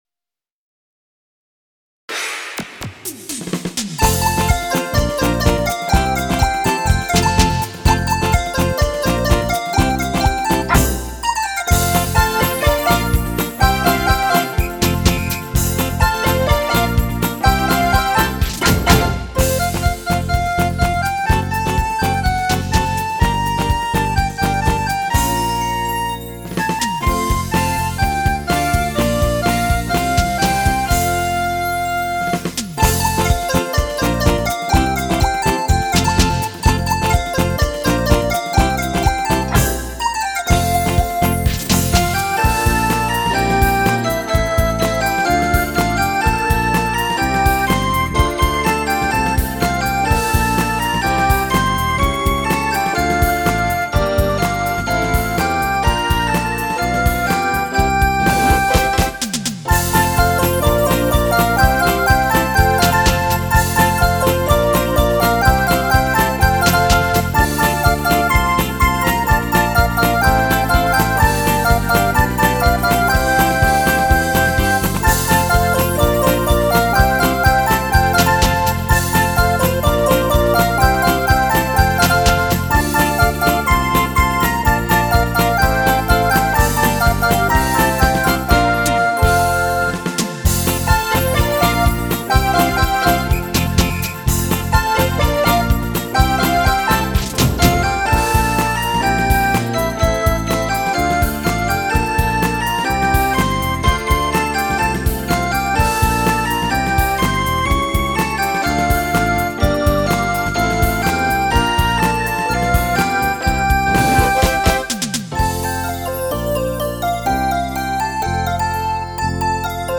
ここでは、いわゆる「J-Pop」が試聴頂けます。
ヤマハエレクトーン「Stagea ELS-01C」
わかりにくいかも知れませんが、ちゃんとムック（犬）の鳴き声も入っています。